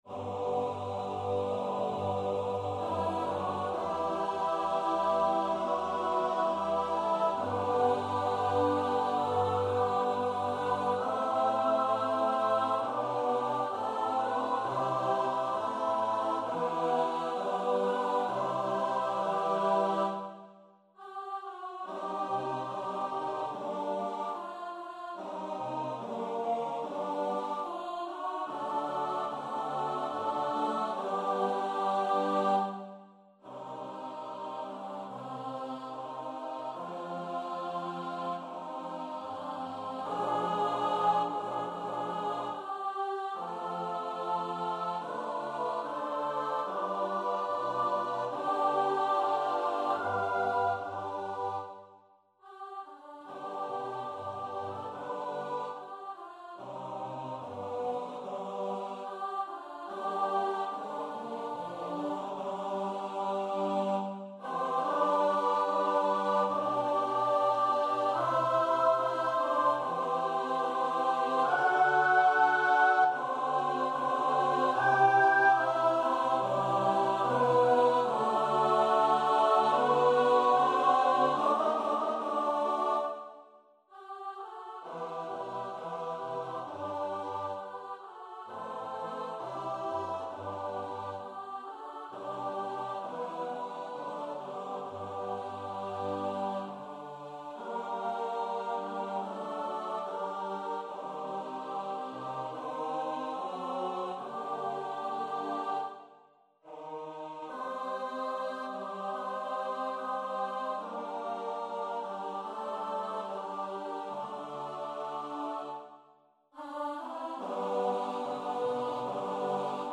Number of voices: 8vv Voicing: SSAATTBB Genre: Sacred, Liturgical music
Language: Church Slavonic Instruments: A cappella